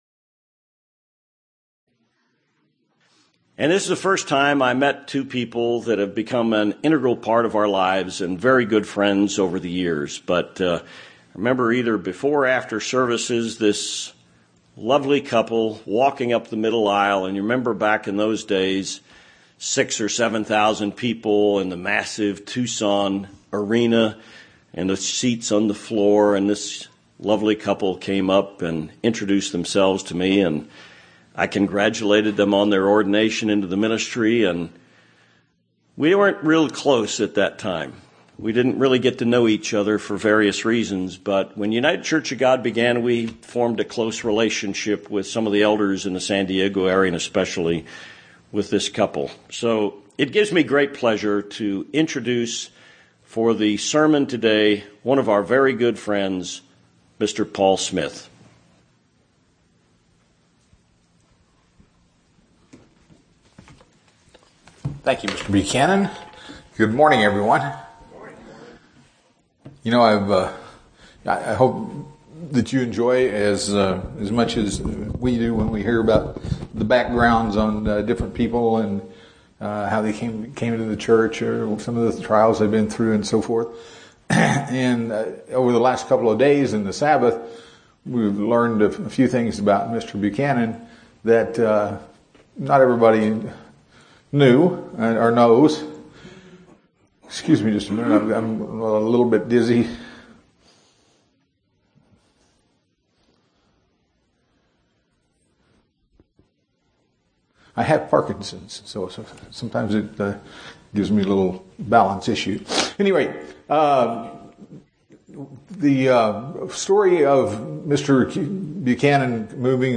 Sermons
Given in Tucson, AZ